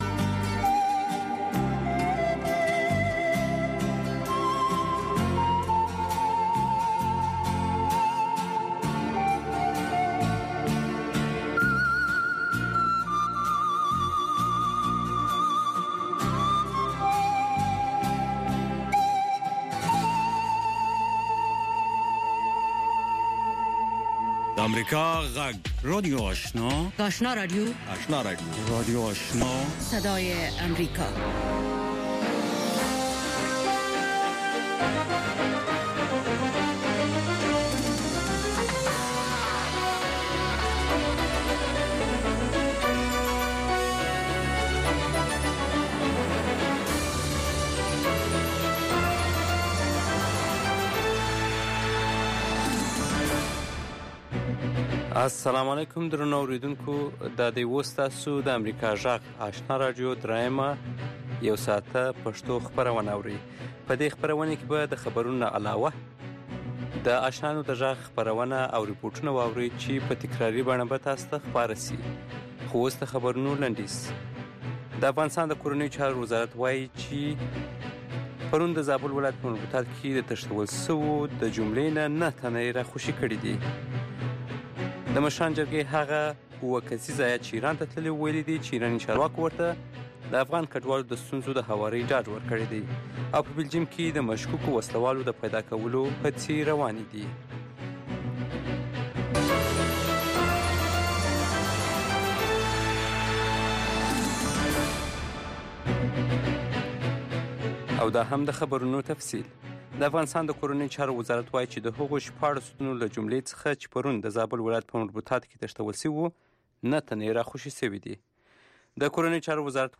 یو ساعته پروگرام: تازه خبرونه، او د نن شپې تېر شوي پروگرامونه ثبت شوي او بیا خپریږي چې د شعر او ادب په گډون هنري، علمي او ادبي مسایل رانغاړي.